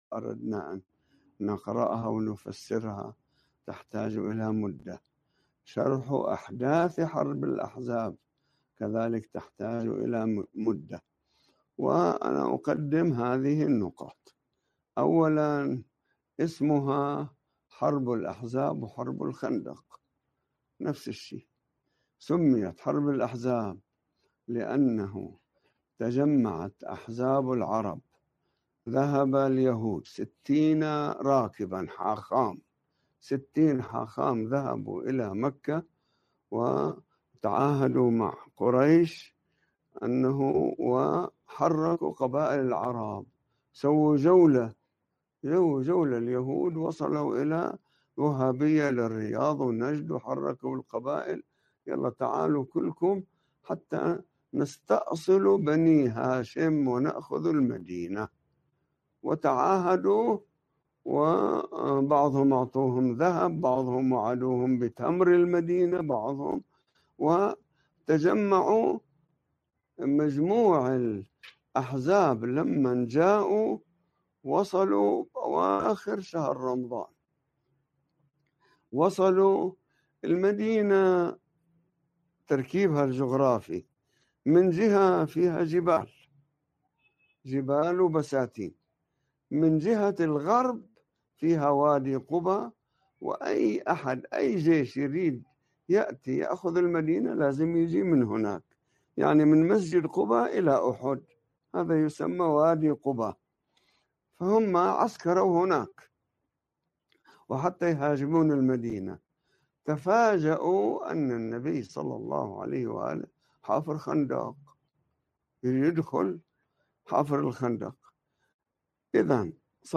الدروس اليومية